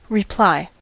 reply: Wikimedia Commons US English Pronunciations
En-us-reply.WAV